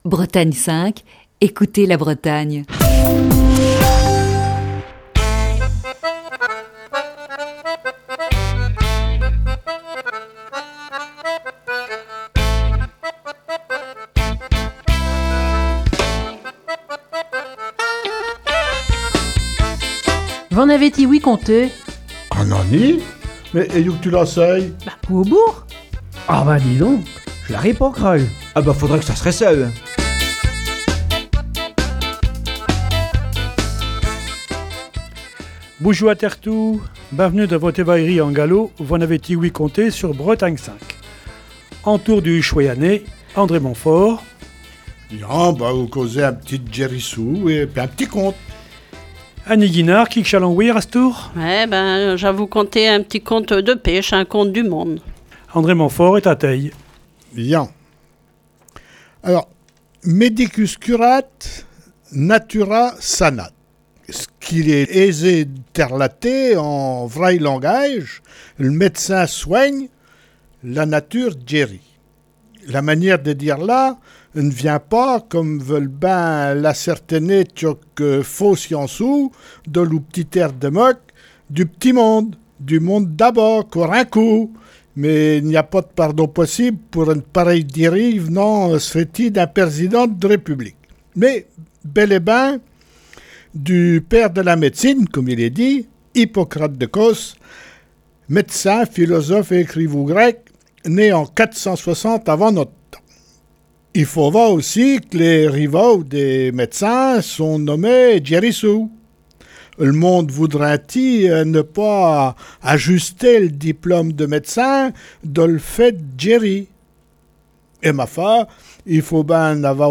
Ce matin, la troupe de V'en avez-ti ouï conté ? est autour du micro pour évoquer les ghérissous.